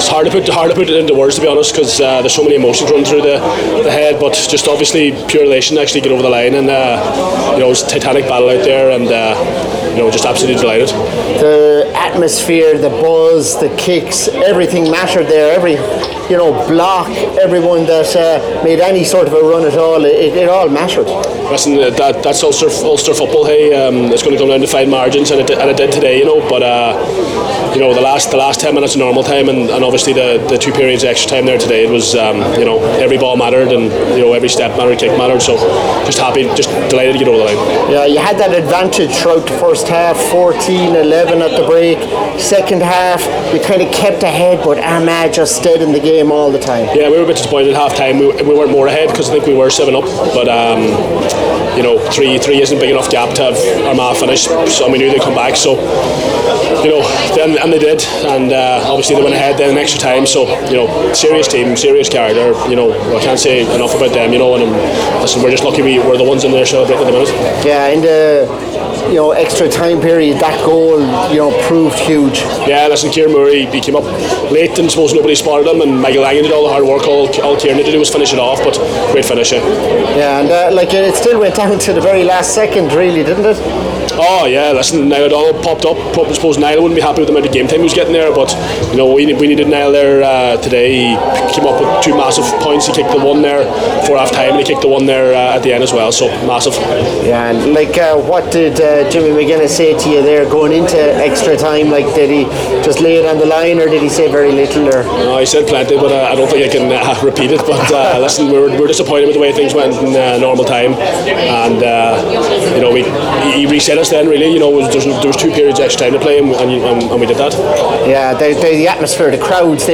Patrick McBrearty spoke with Newstalk/Off The Ball after the game…